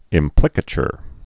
(ĭm-plĭkə-chər)